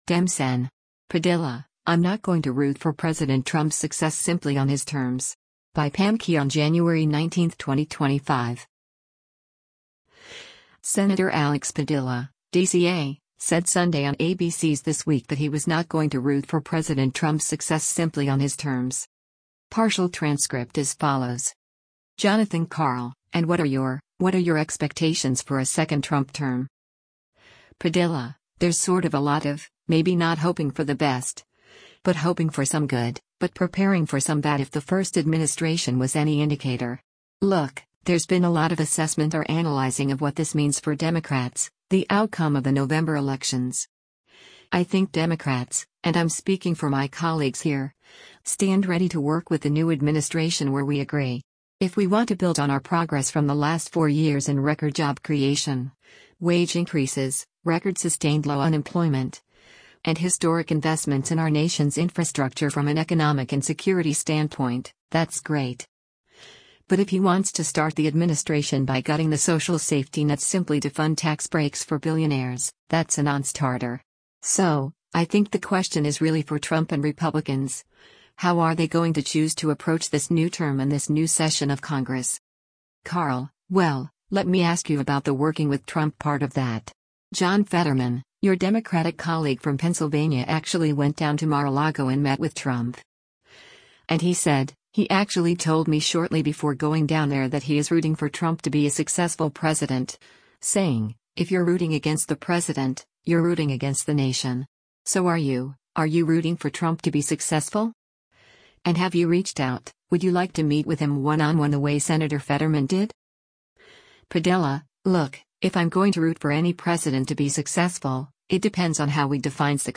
Senator Alex Padilla (D-CA) said Sunday on ABC’s “This Week” that he was “not going to root for President Trump’s success simply on his terms.”